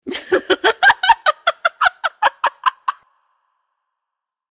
Intense Female Laughter Sound Effect
A woman laughs loudly and uncontrollably, expressing cheerfulness and euphoria. Human sounds.
Intense-female-laughter-sound-effect.mp3